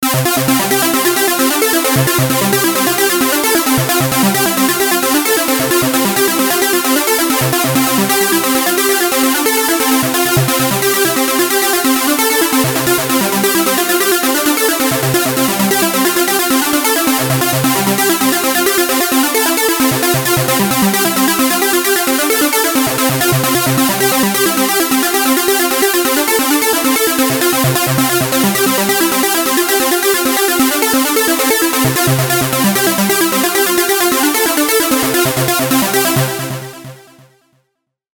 In following MP3 sample a static sequence has been varied with different Step Fwd/Jump Back/Replay values while it was playing:
mbseqv3_progression.mp3